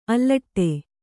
♪ allaṭṭe